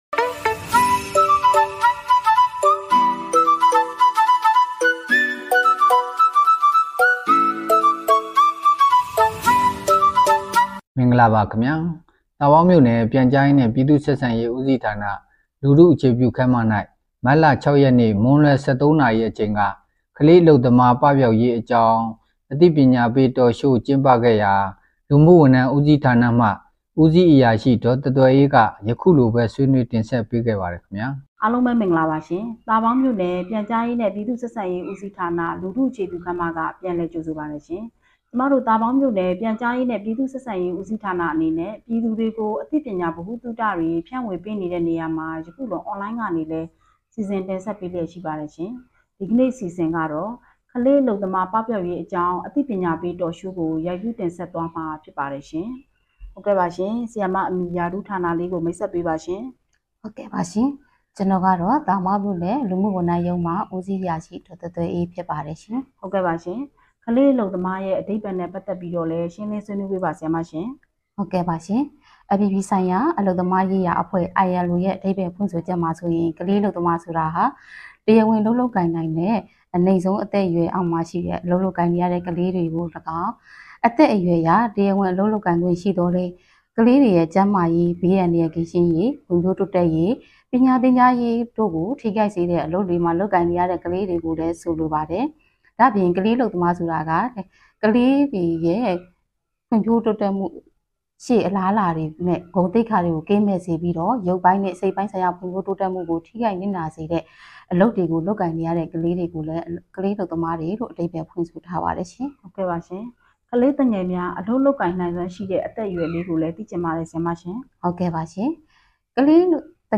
Community Centre ခန်းမတွင် ကလေးအလုပ်သမားပပျောက်ရေးအသိပညာပေး Talk Show ပြုလုပ် သာပေါင်း မတ် ၇ ပေးပို့သူ - မြို့နယ်ပြန်/ဆက်